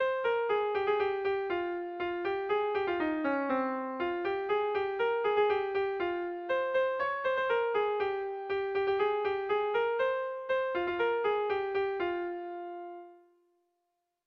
Erlijiozkoa